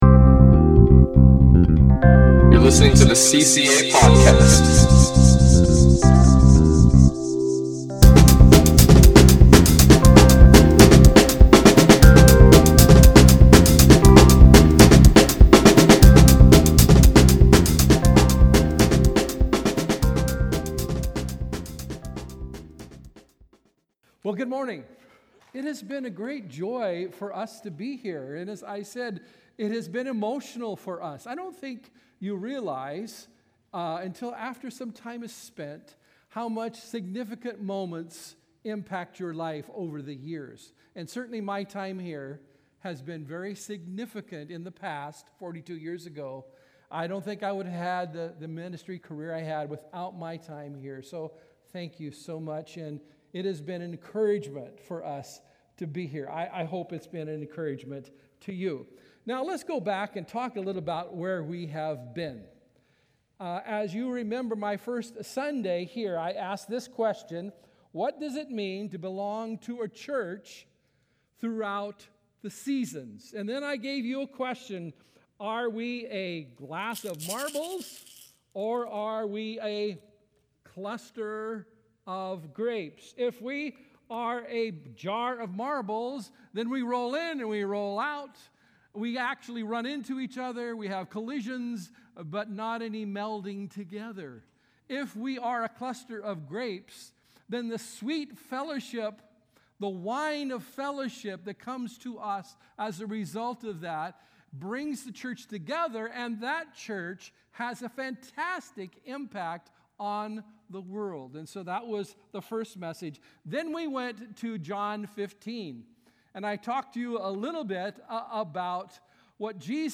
Listen to Message | Download Notes